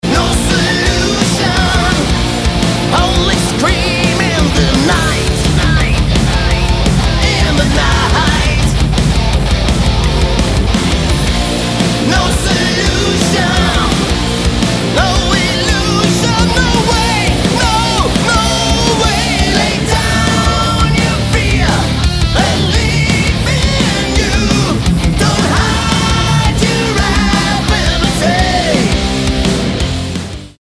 Lead Vocals
Bass, Vocals
Gitarre, Vocals
Drums, Vocals